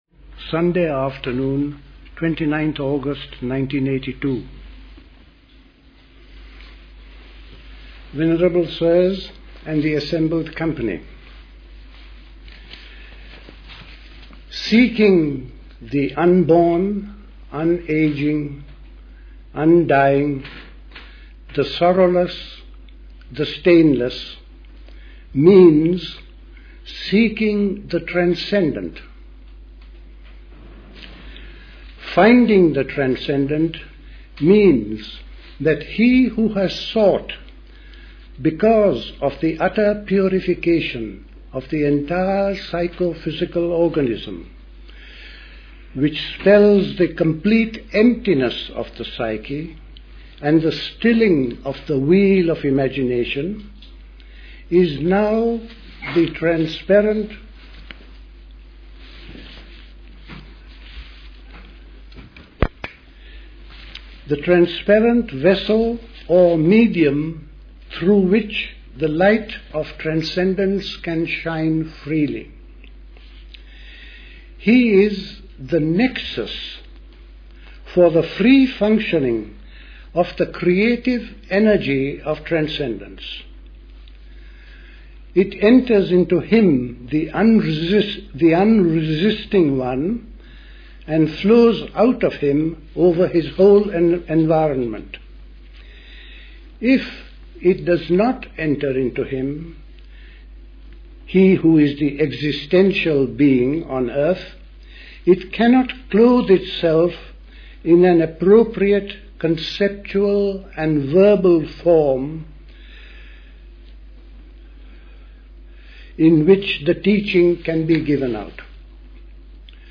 A talk
at High Leigh Conference Centre, Hoddesdon, Hertfordshire